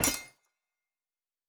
Fantasy Interface Sounds
Blacksmith 05.wav